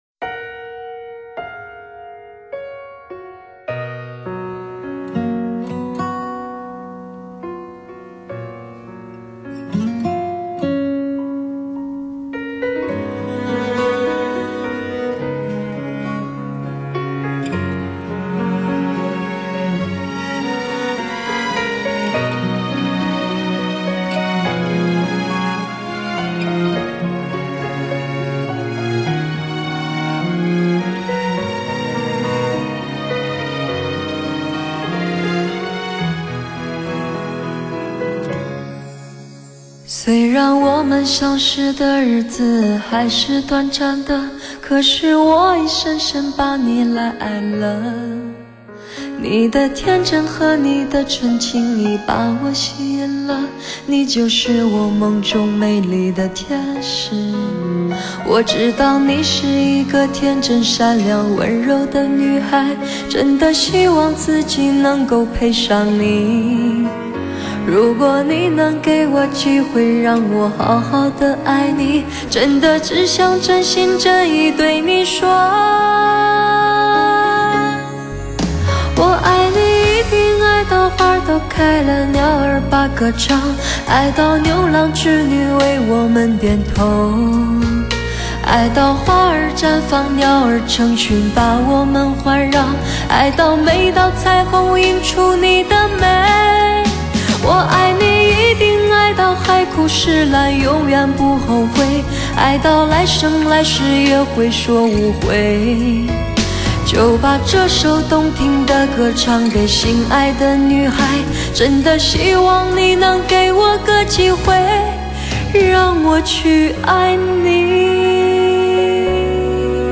细腻而深带忧伤的吟唱
音场深阔 定位精确 音质纯美 录音之冠